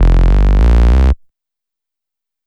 FK097BASS1-R.wav